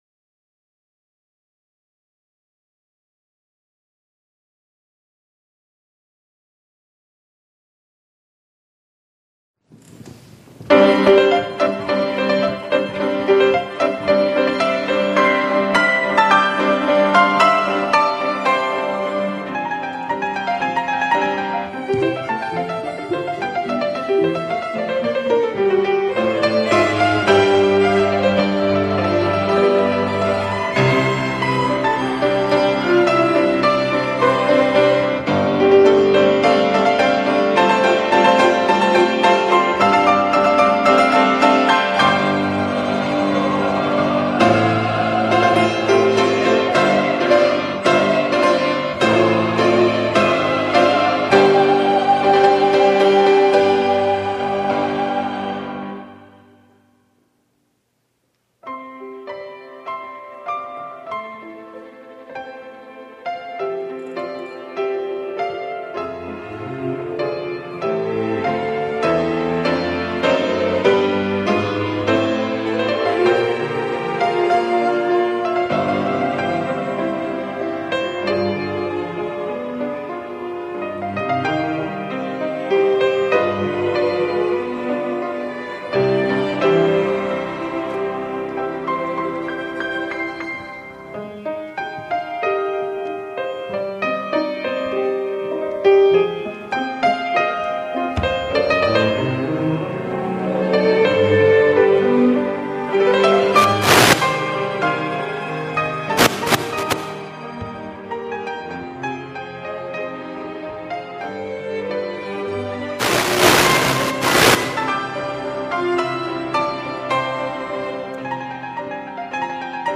2025 성탄절 칸타타